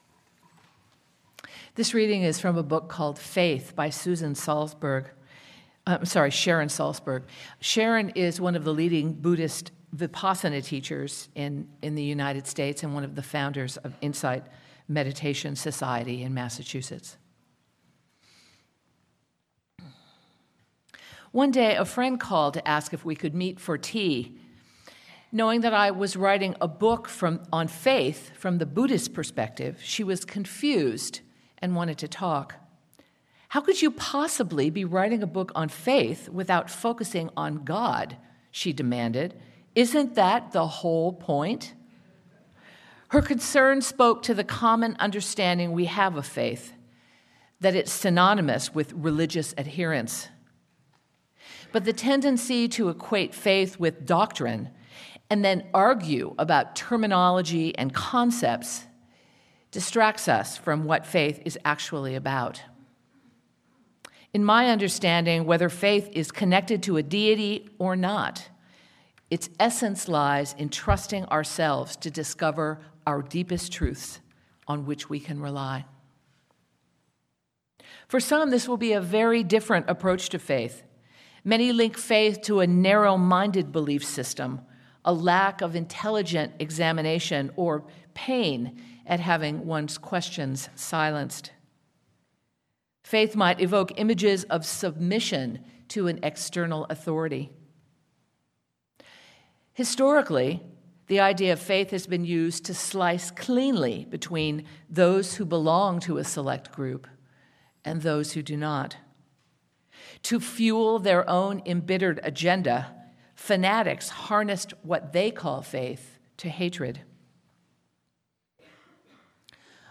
Sermon-From-Fear-to-Faith.mp3